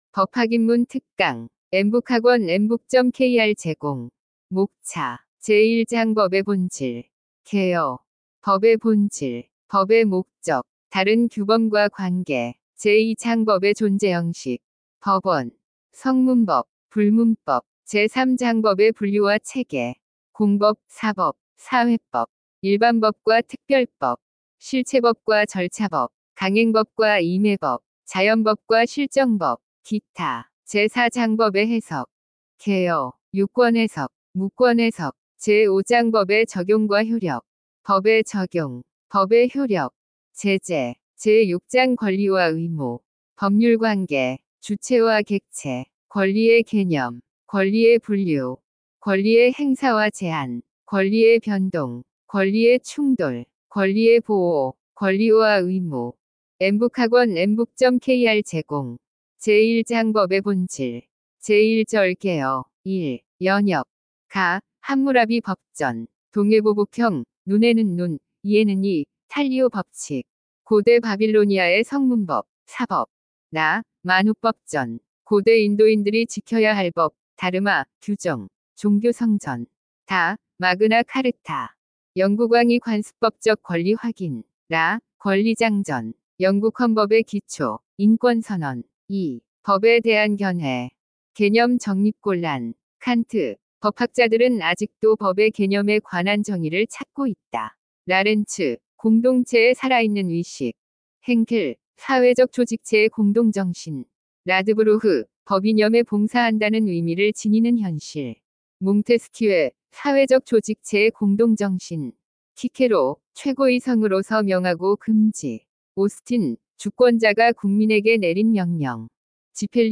엠북학원은 우리나라 최초의 인공지능이 강의하는 사이버학원이며, 2025년 4월 28일 개원하였습니다.
법학입문-특강-샘플.mp3